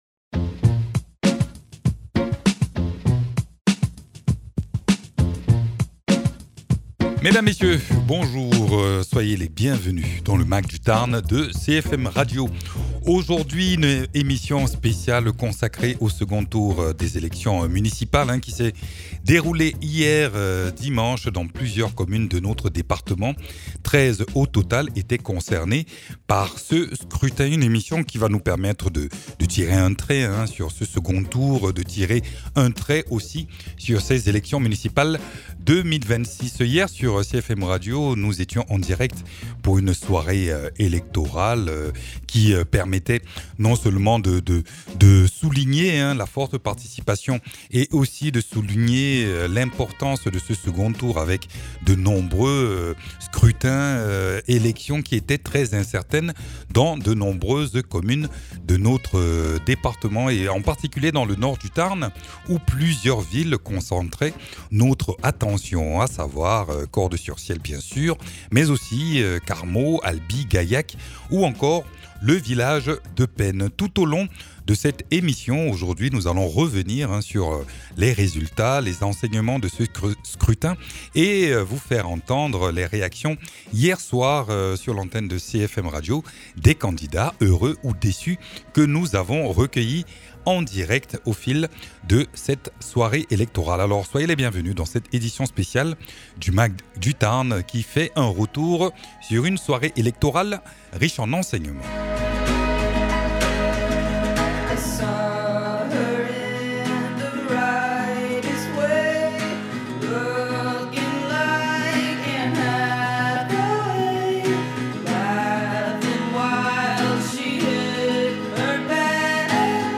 Carmaux, Albi, Gaillac, Cordes-sur-Ciel et Penne : plusieurs communes du Tarn étaient au cœur du second tour des élections municipales ce dimanche. Dans ce numéro, revenons sur les résultats et les réactions à chaud au cours d’une soirée électorale marquée par une forte participation des électeurs et des scrutins incertains pour la plupart.
Interviews